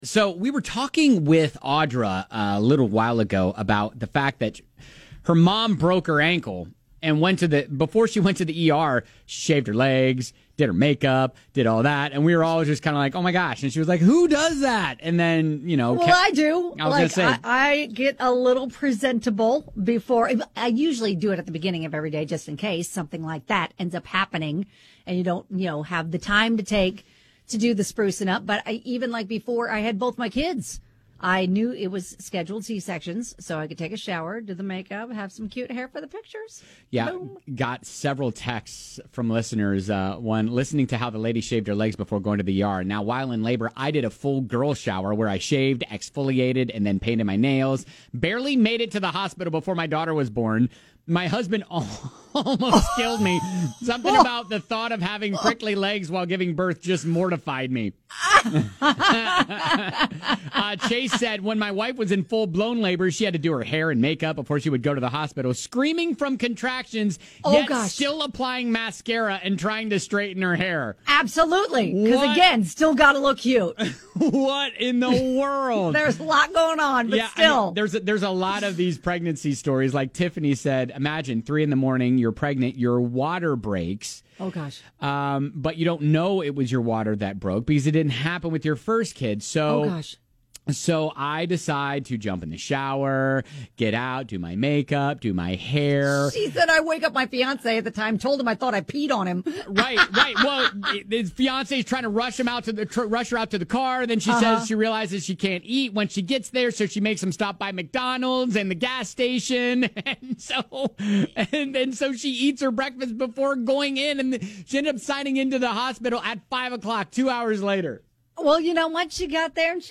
More calls and stories about women getting themselves "ready" to go to the hospital!